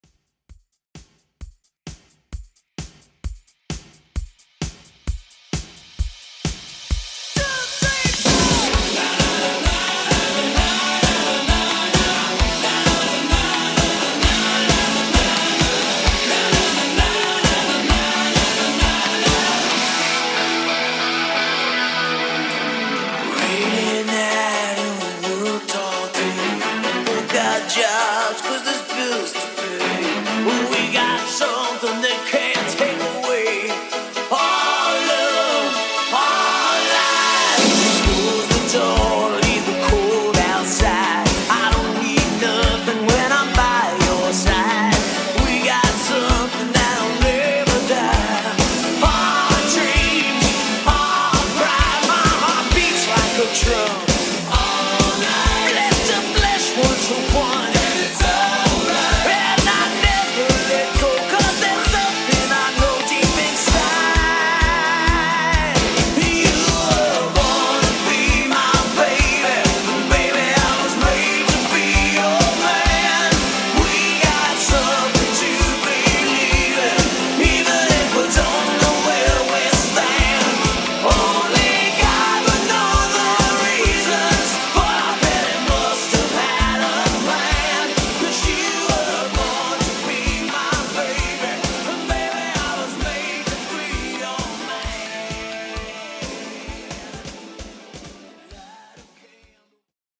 Rock ReDrum)Date Added